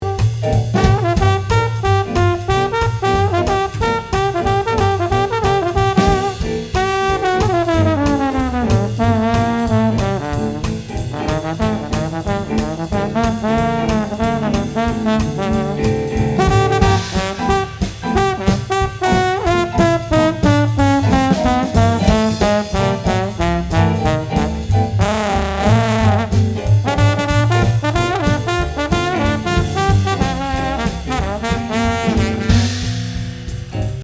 un dernier pour la route le D2 pour le rimshot/CC et même kit qu'en haut.... enfin pour être franc : j'ai mis cet extrait seulement pour vous faire profiter du couple RE20 plus très bon trombone => détonnant (et sans compression sur la source s'il vous plaît)
enfin, le son du rimshot+D2 n'est pas mal non plus.... :)
1114big-fat-trombone.mp3